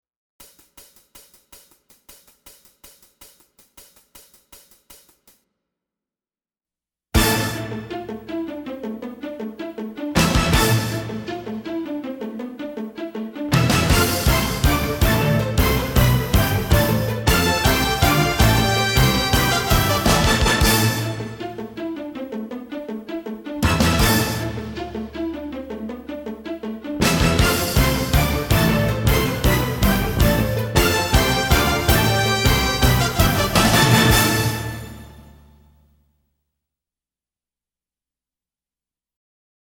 Eclipse with Drum click.mp3